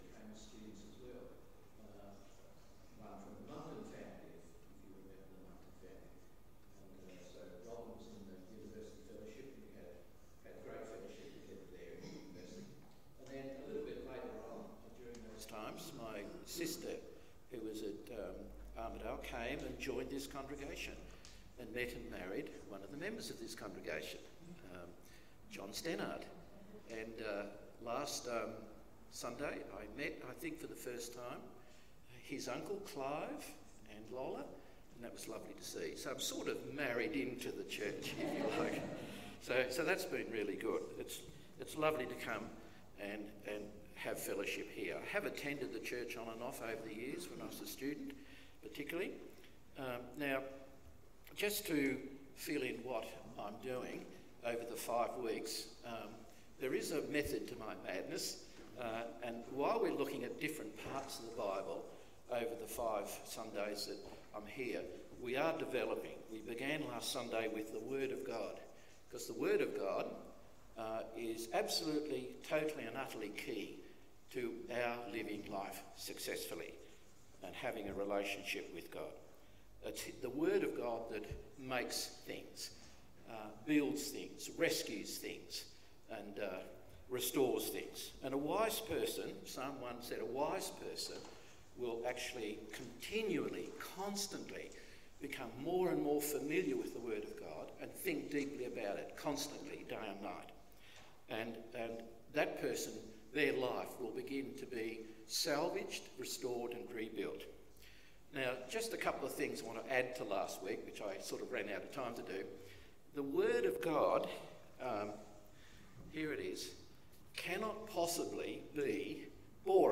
The second in a series of five sermons.
Service Type: AM Service